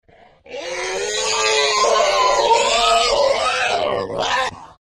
Alien Scream; Angry Or Anguished Creature Vocals.